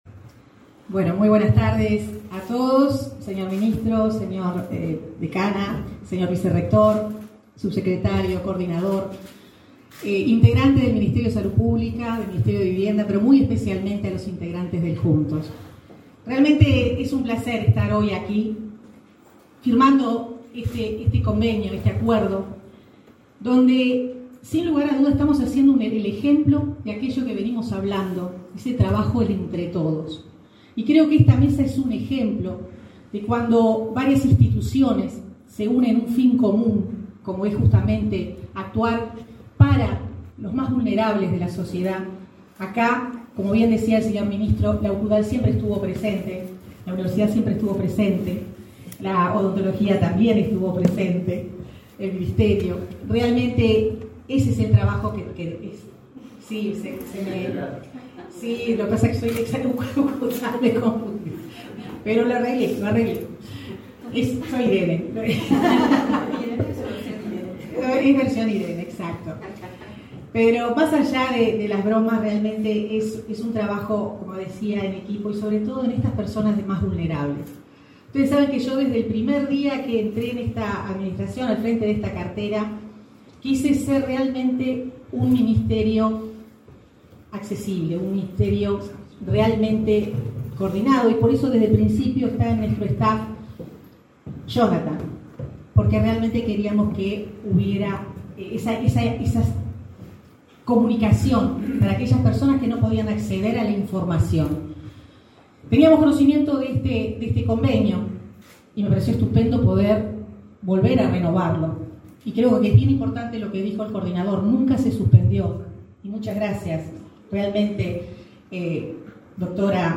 Conferencia de prensa por firma de convenio entre MVOT, MSP y Juntos
El Ministerio de Vivienda y Ordenamiento Territorial (MVOT), el Ministerio de Salud Pública (MSP), Juntos, y la Universidad de la República firmaron, el 6 de febrero, un convenio para implementar el plan de salud bucal integral a participantes del Juntos, así como a población definida por los ministerios, según las situaciones particulares. Participaron del evento la ministra Irene Moreira, el ministro Daniel Salinas y el coordinador del Juntos, Rody Macías.